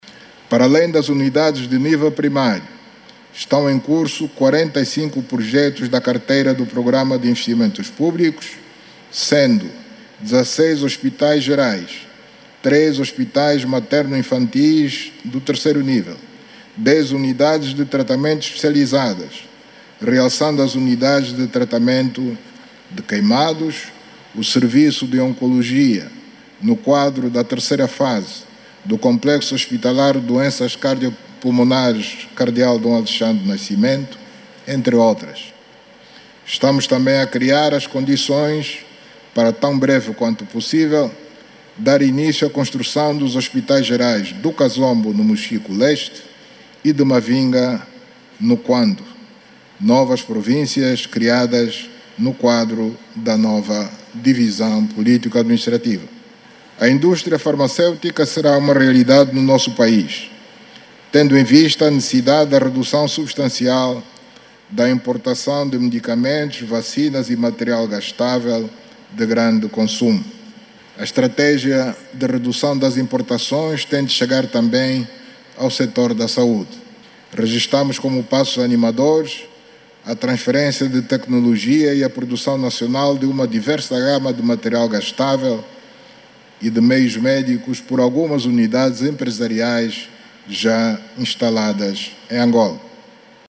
O presidente da República, João Lourenço, afirmou hoje que os investimentos no sector da saúde permitiu a construção de um sistema nacional de saúde  mais robusto. João Lourenço fez esta afirmação no Parlamento quando proferia a Mensagem sobre o Estado da Nação.